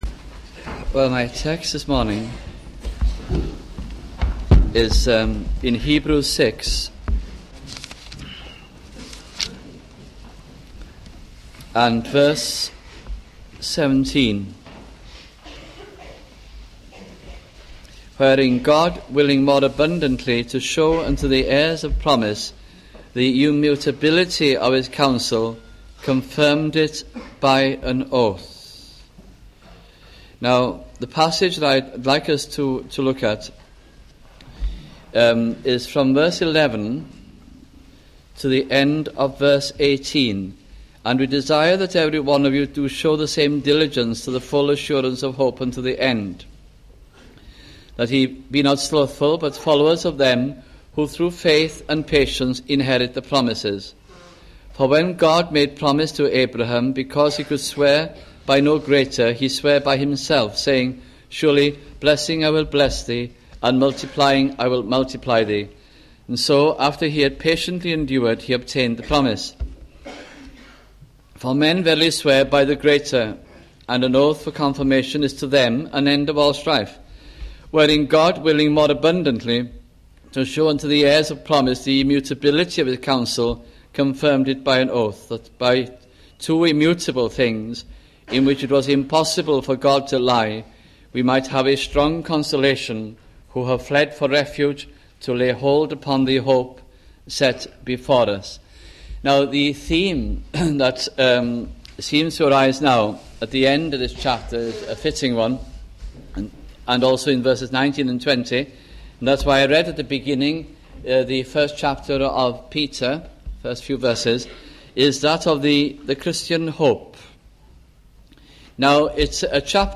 » The Epistle to the Hebrews 1984 - 1986 » sunday morning messages